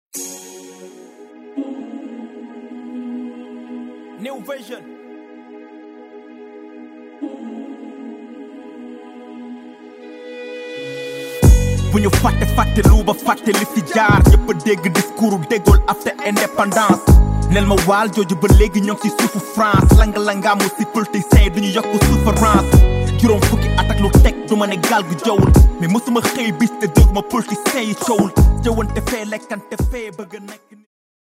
Un album 100% Rap mêlant émotions, poésie et mélodies